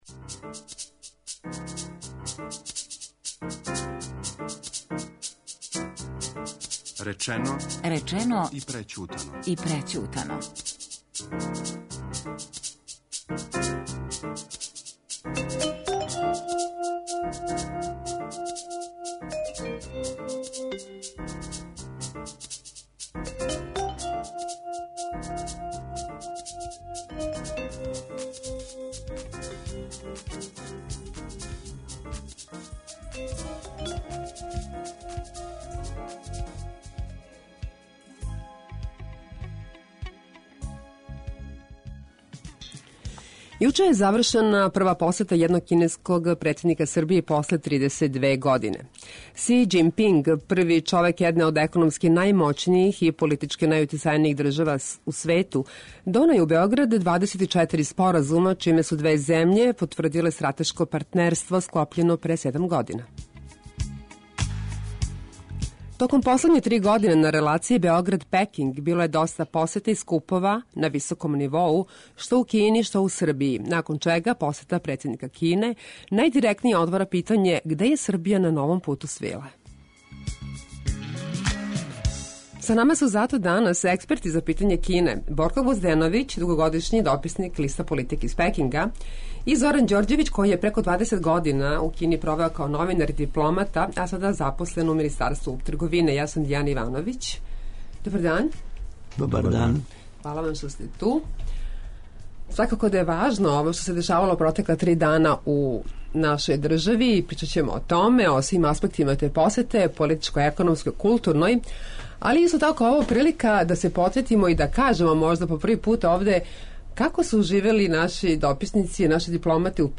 у којој говоре наши експерти за Кину